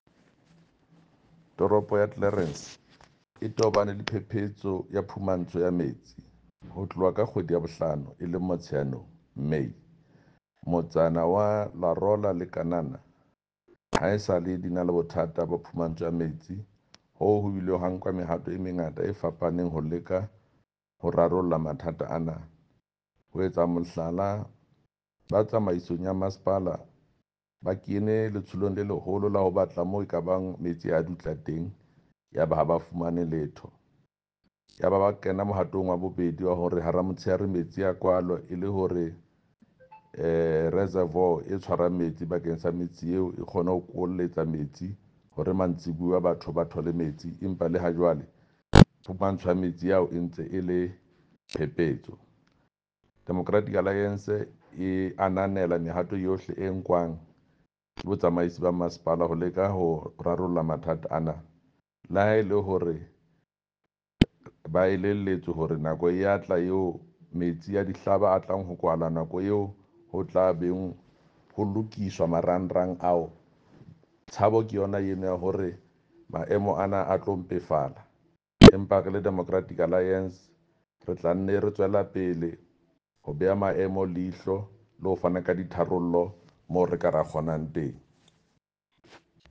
Sesotho from Jafta Mokoena MPL.
Sotho-voice-Jafta-5.mp3